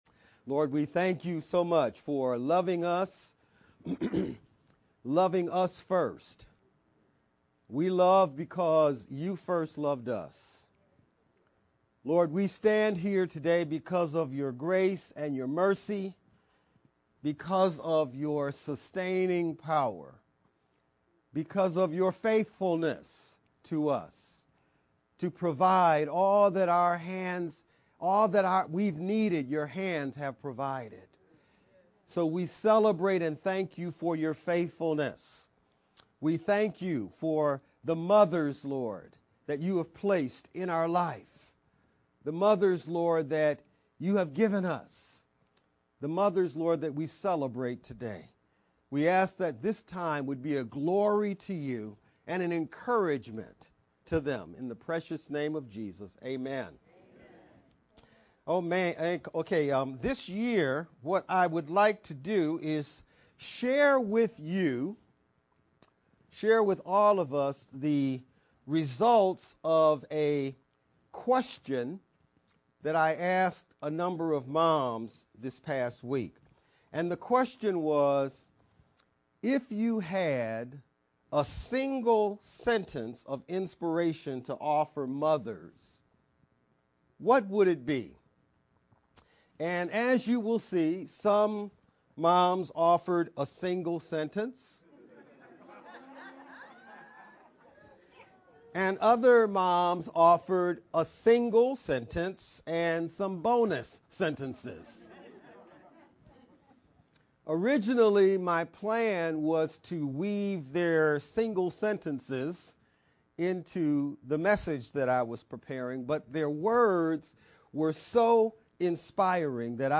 5-14-VBCC-Sermon-edited-sermon-only-Mp3-CD.mp3